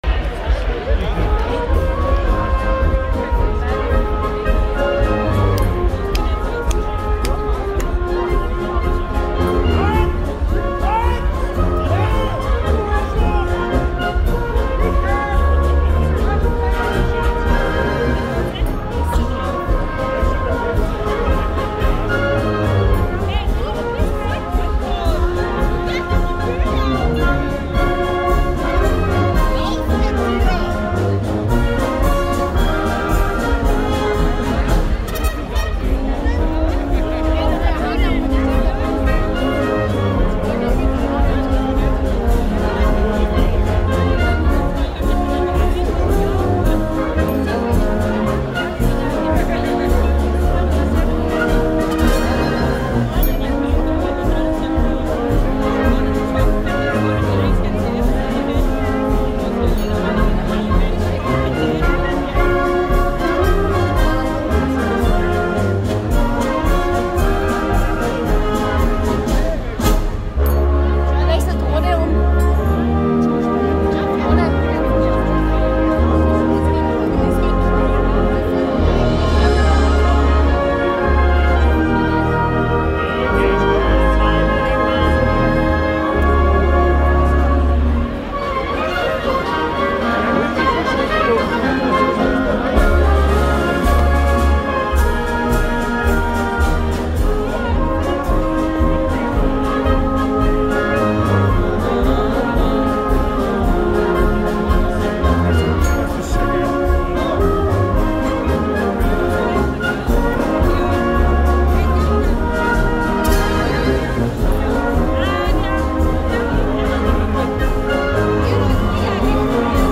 Gattung: Für kleine Besetzung
Besetzung: Kleine Blasmusik-Besetzung